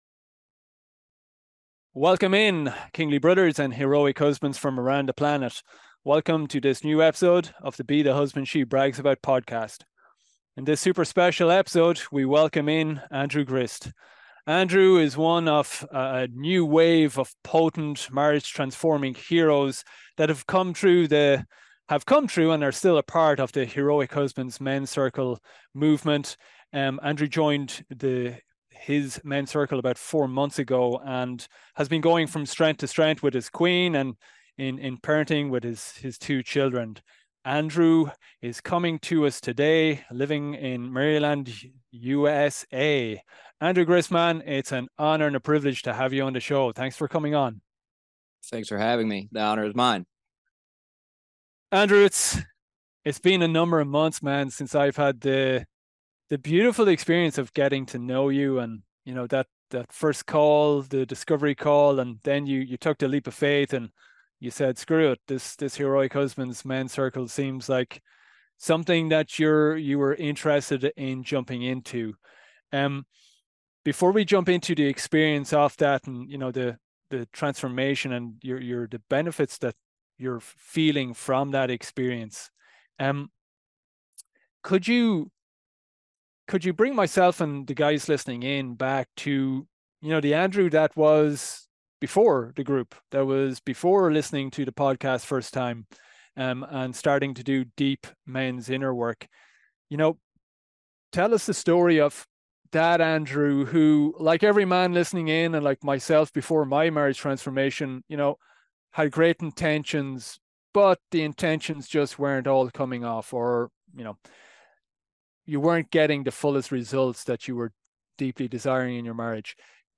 Don’t miss his impromptu rapping performance of masculine spiritual poetry near the end.